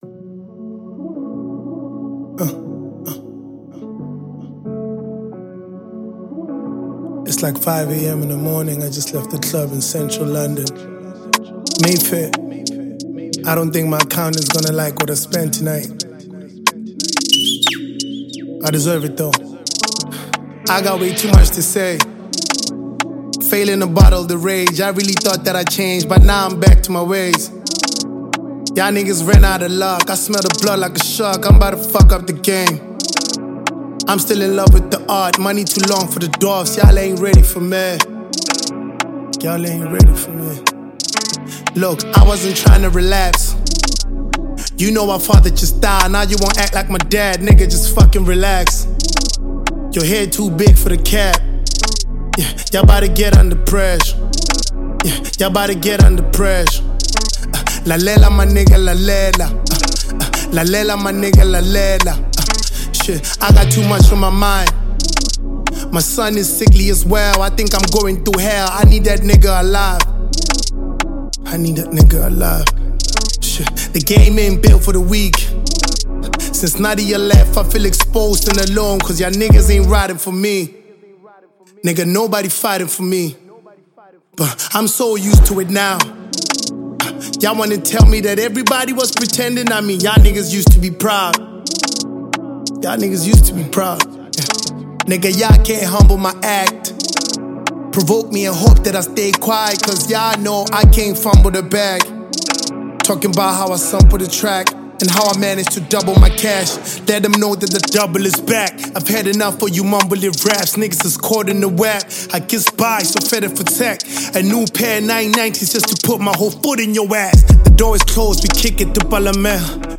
South African veteran rapper and singer